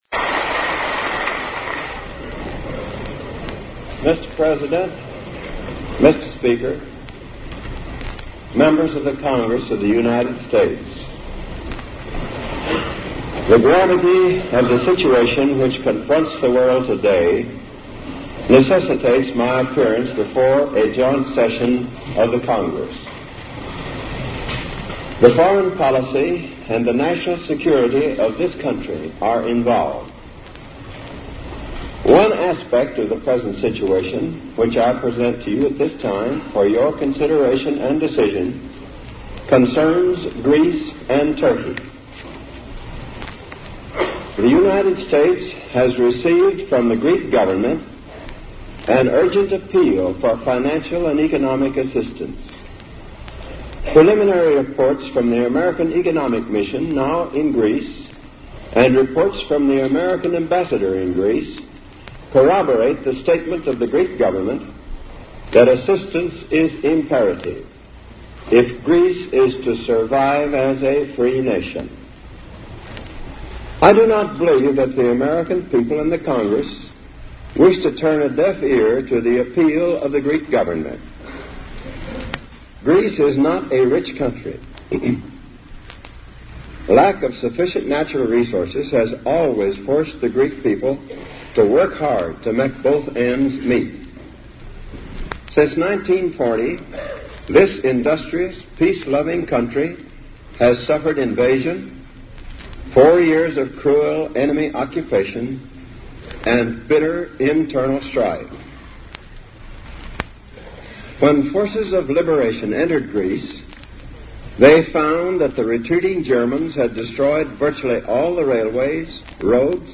Delivered 12 March 1947 before a Joint Session of Congress